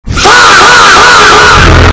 FAHHHHHHHH EARRAPE Sound
FAHHHHHHHH EARRAPE meme soundboard clip with extremely loud distorted fah sound effect for chaotic and shocking reactions.